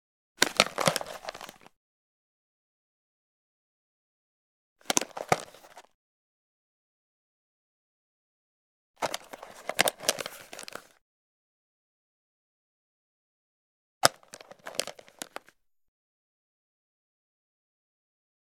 Camera Vintage Leather Case Wrap Around Style Open Sound
household